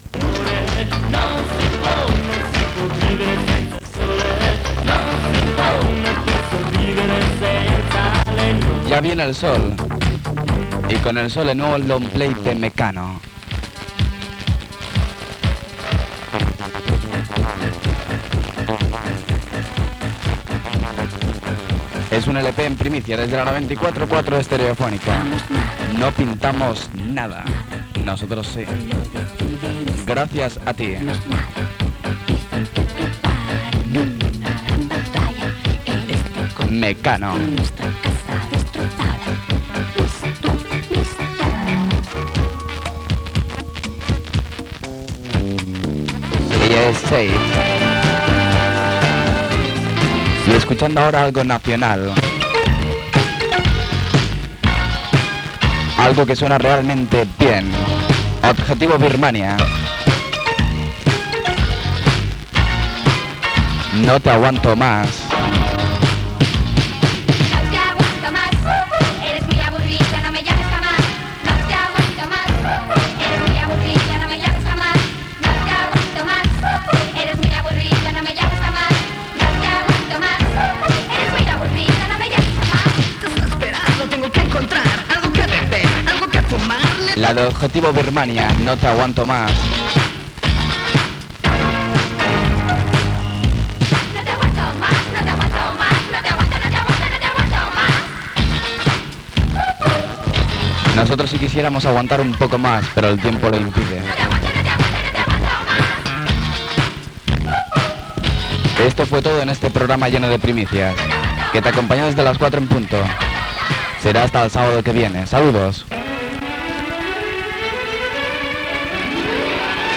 Temes musicals, identificació de la cadena i de l'emissora i promoció de "Los Super 30".
Musical